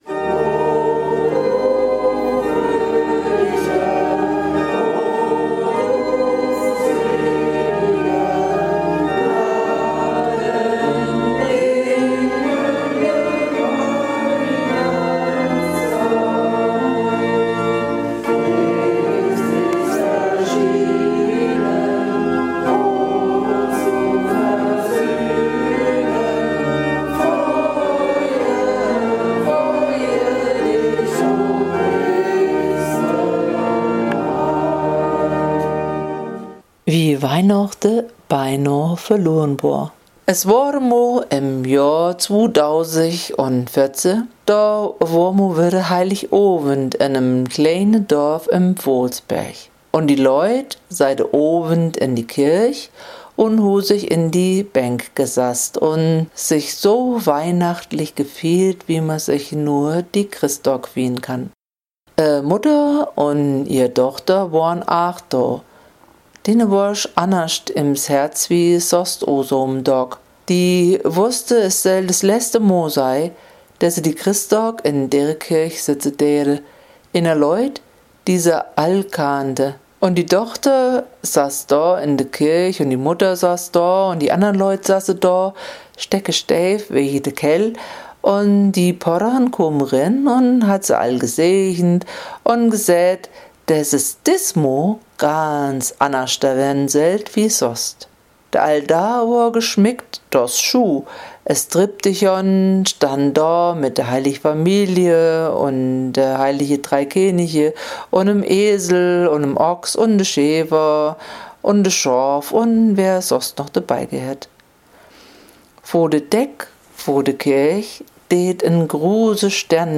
Weihnachten-mit-Musik-online-audio-converter.com_.mp3